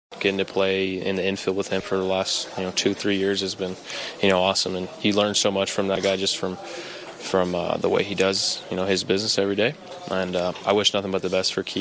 Second baseman Nick Gonzalez says he will miss Hayes.